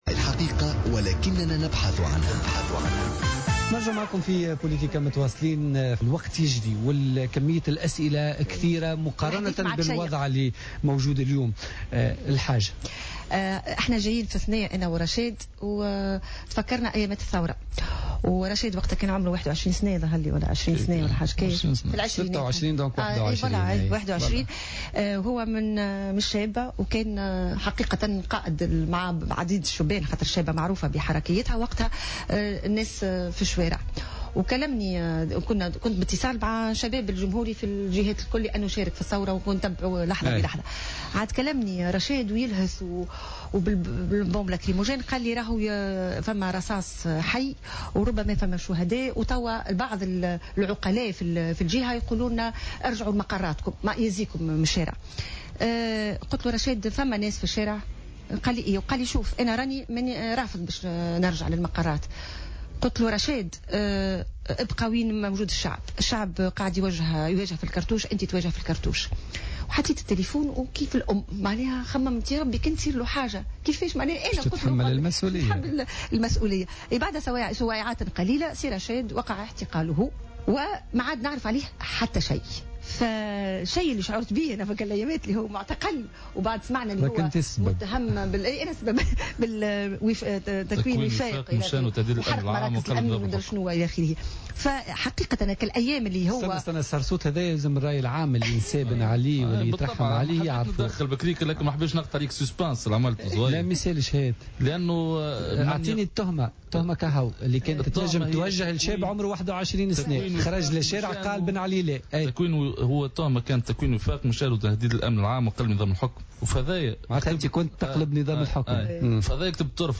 وأضافت في لقاء في برنامج "بوليتيكا" على "الجوهرة أف أم" أن تونس تحتاج إلى نظام ديمقراطي اجتماعي يمكّن من إرساء عدالة اجتماعية.